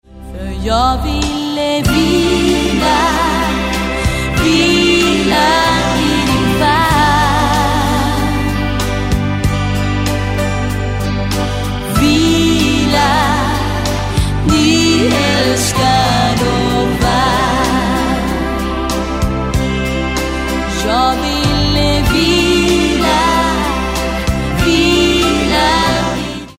VÅRENS STORA DANSBANDS SAMLING!